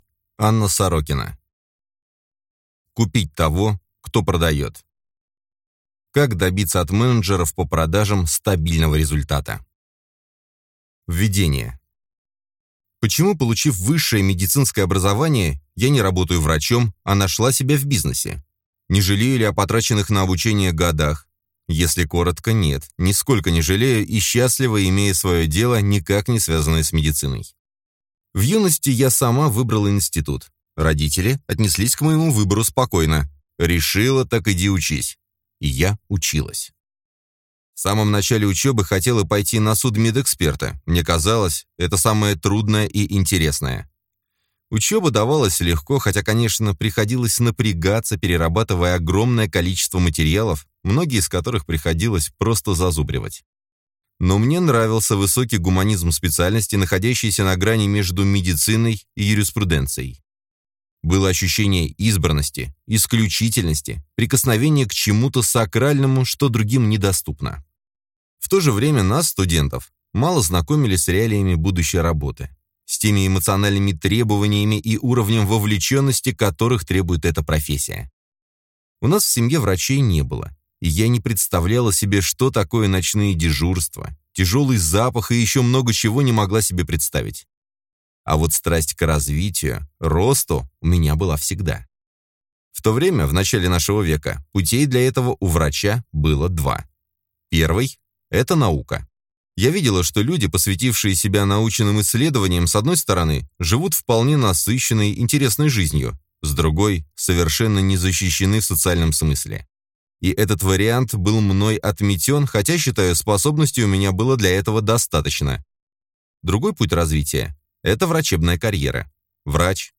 Аудиокнига Купить того, кто продает. Как добиться от менеджеров по продажам стабильного результата | Библиотека аудиокниг